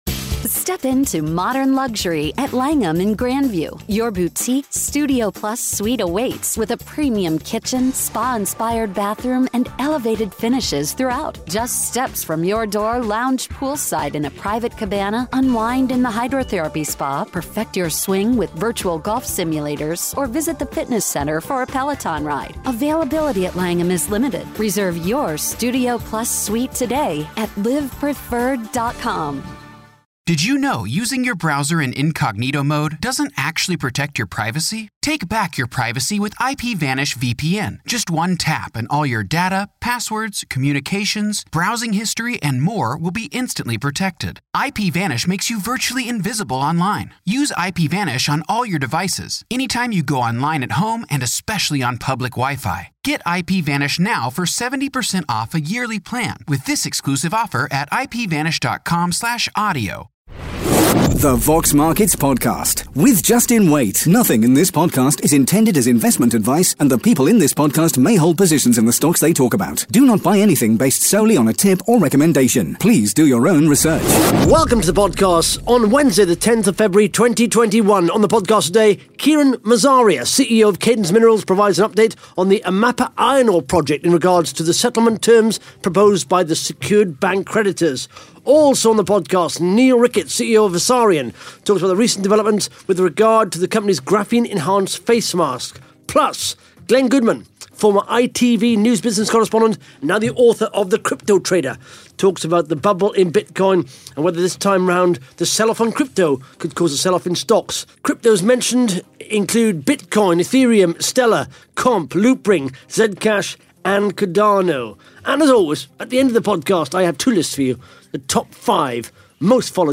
(Interview starts at 12 minutes 3 seconds)